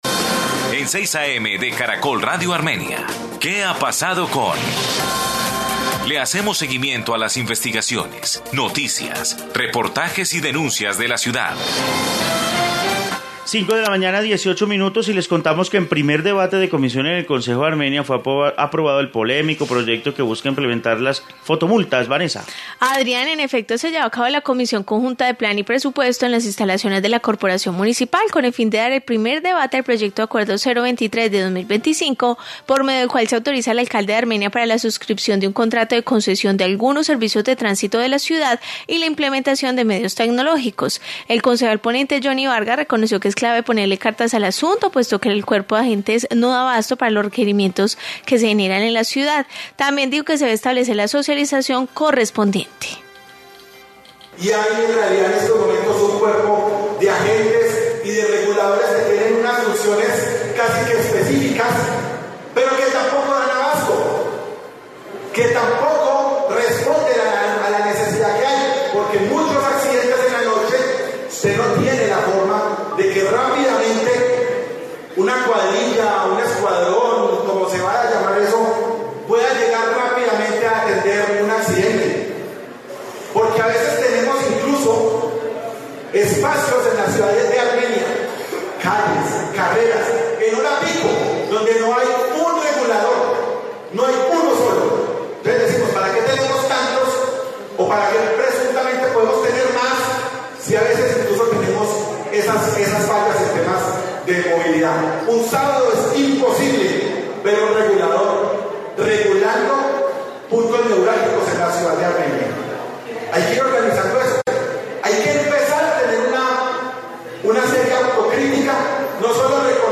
Informe sobre proyecto en el Concejo